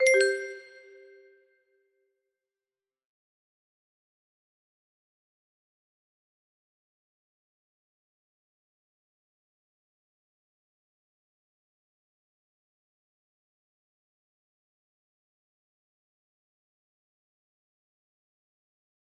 ClockOut music box melody